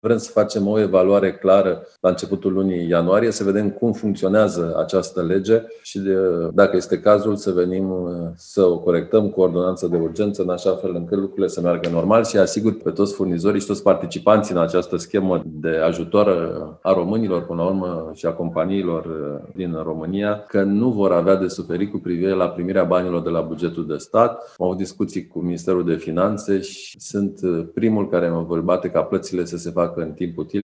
La o dezbatere organizată de Focus Energetic, ministrul a spus că ne așteaptă o iarnă complicată din cauza turbulențelor de pe piața externă, dar că avem suficiente stocuri de gaze, cărbune și energie electrică pentru a trece cu bine de sezonul rece.